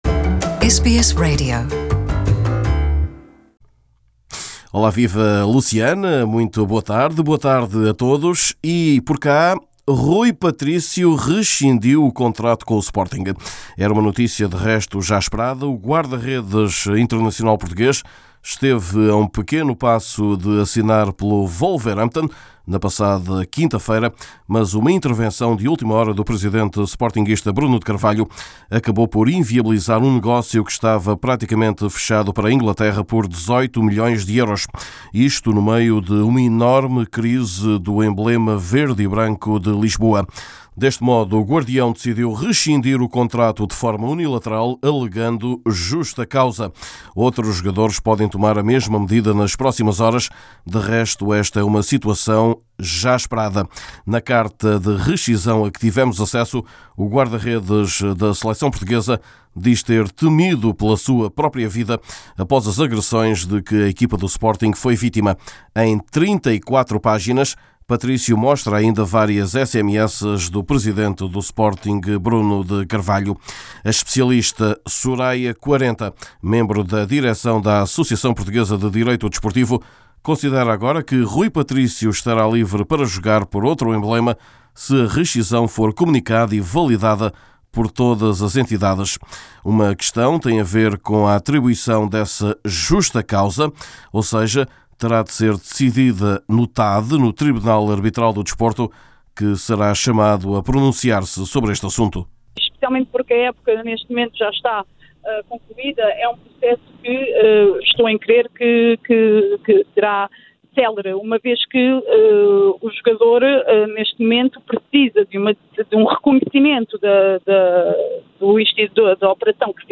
Em seu boletim semanal esportivo